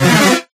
Attack1.ogg